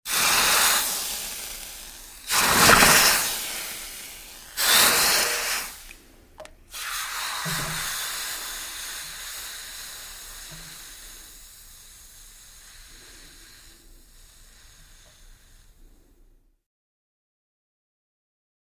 Звуки утюга
Шипение горячего утюга при встрече с водой